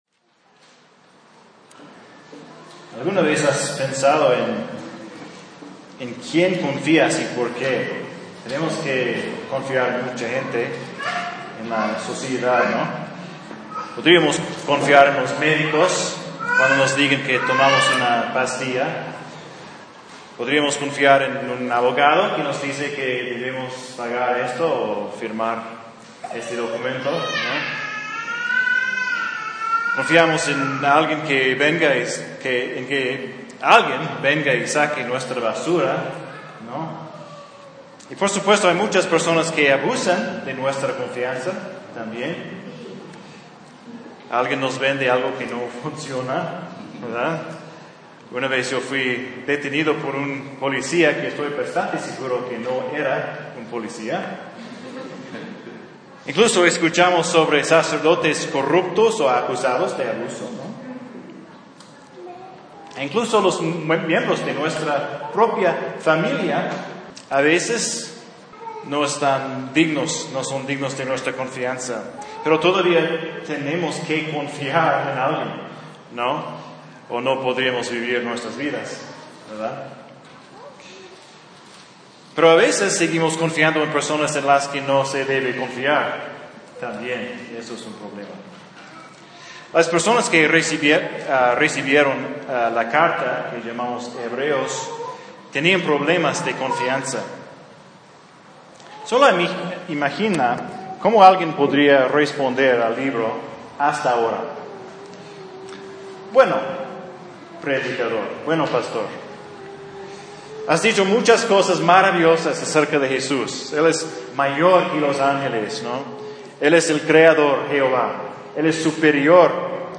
Un sermón de Hebreos 4:15-5:10.